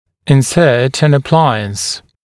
[ɪn’sɜːt ən ə’plaɪəns][ин’сё:т эн э’плайэнс]вводить аппарат (о съемном аппарате)